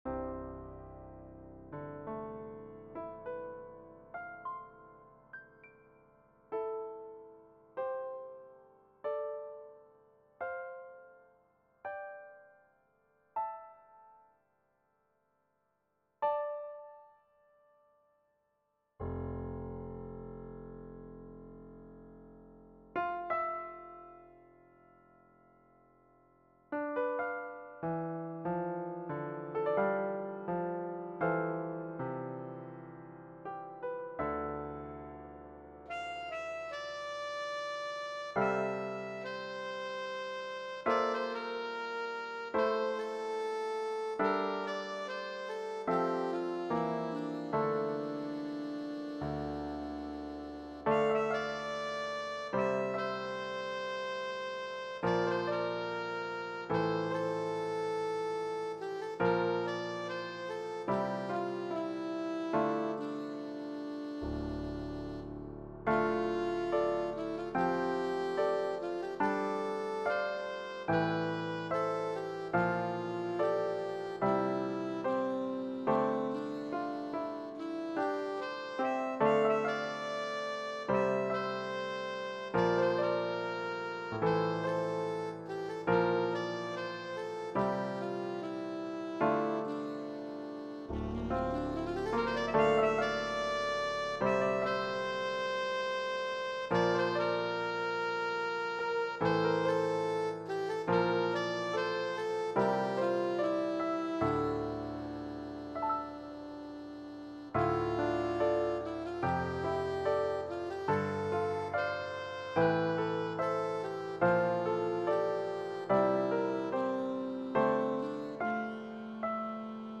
Saxophone and Keyboard
for saxophone and piano